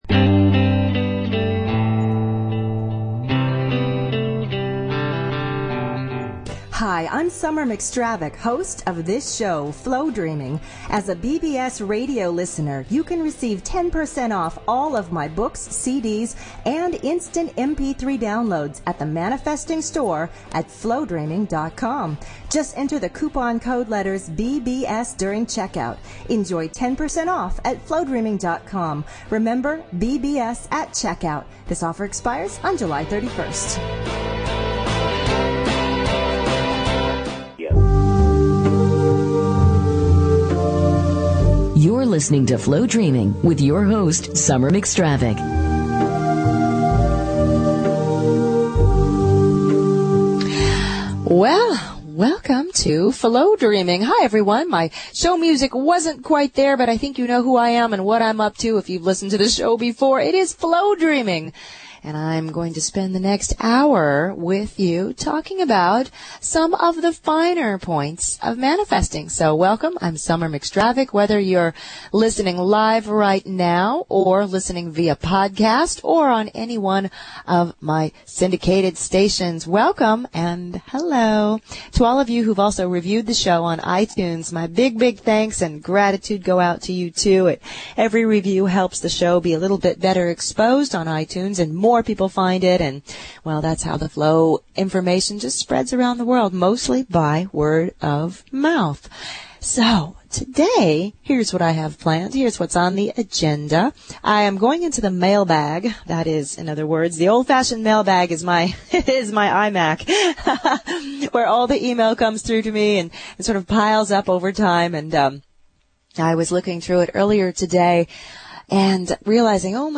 Talk Show Episode, Audio Podcast, Flowdreaming and Courtesy of BBS Radio on , show guests , about , categorized as
Flowdreaming is a fun, fast-paced show about manifesting and Flow energy.